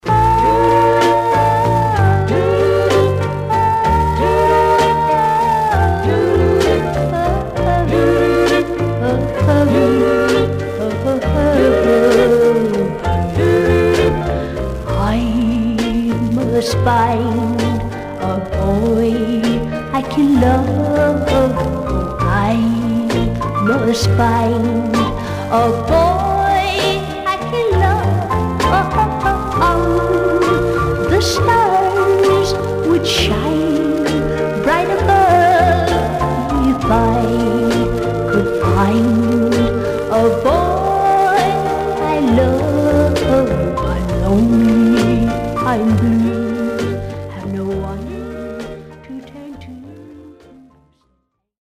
Some surface noise/wear
Mono
Teen